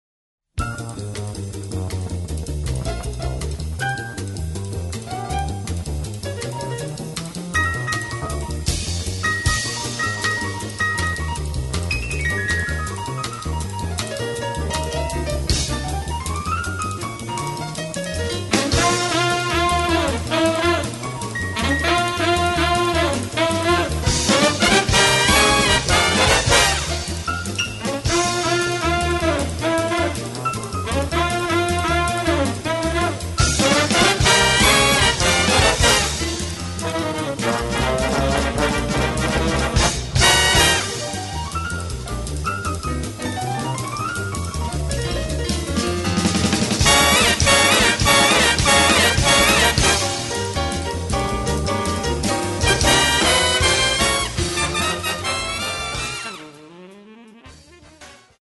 17 členný orchester
Počas produkcie orchestra spievajú aj minimálne 4 sólisti.